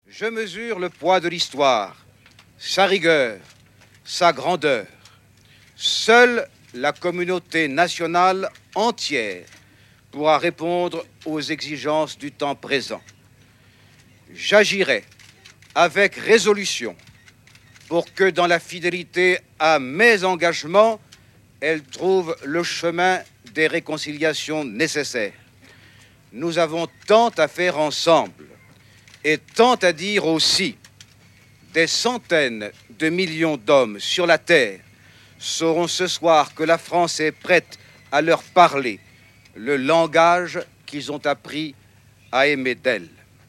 Mitterrand, François : Déclaration (Podcast)